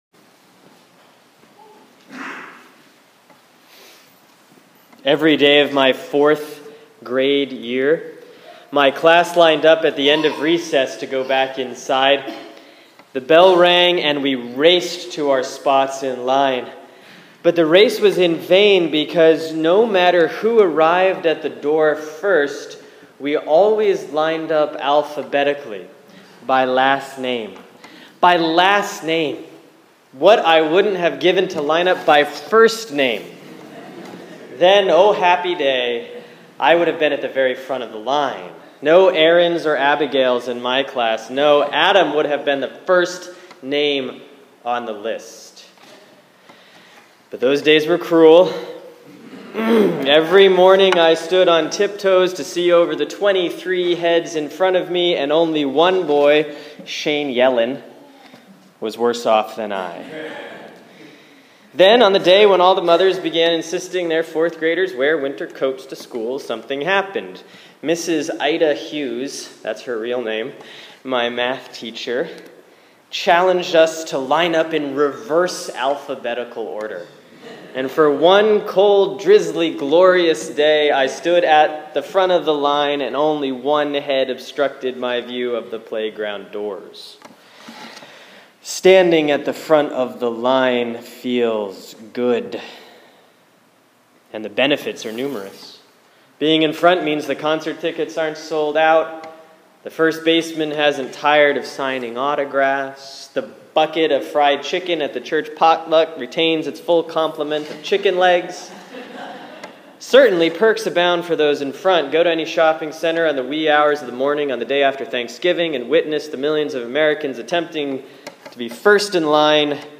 Sermon for Sunday, September 20, 2015 || Proper 20B || Mark 9:30-37